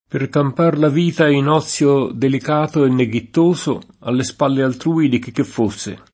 chicchessia [kikkeSS&a] (meno com. chi che sia [id.]) pron.